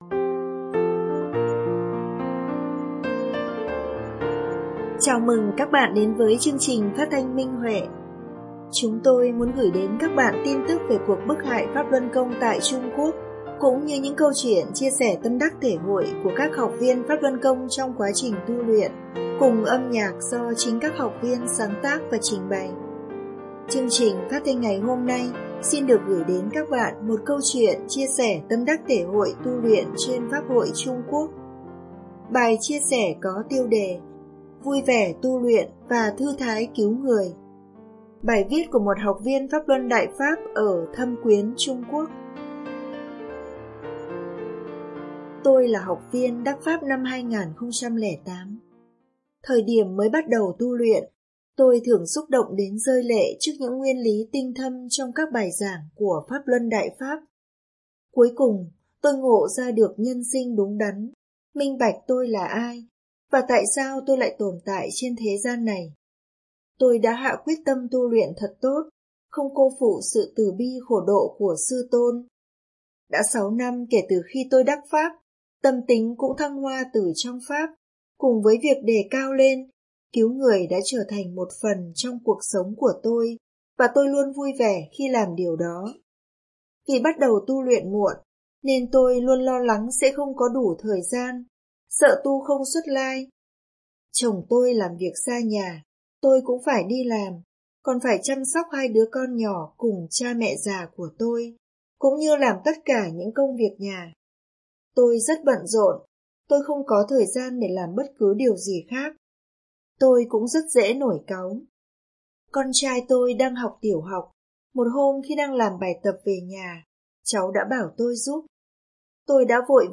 Chào mừng các bạn đến với chương trình phát thanh Minh Huệ.